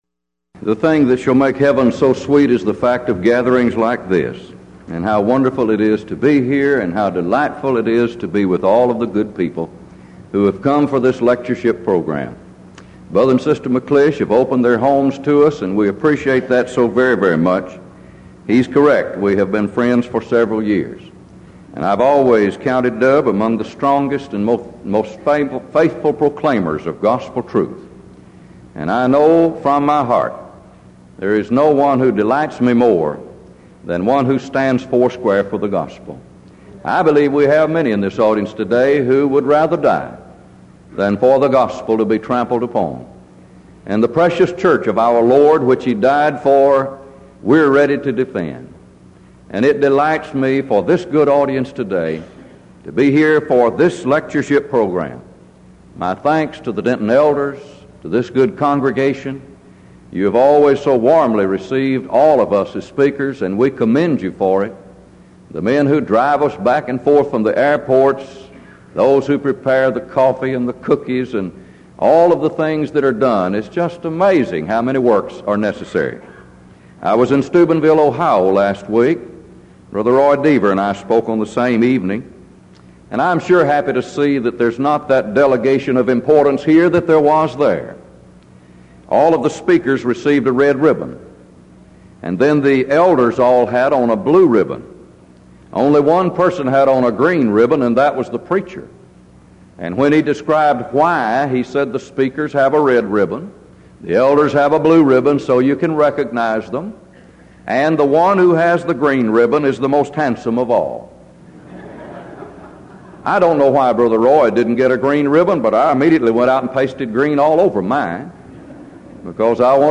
Denton Lectures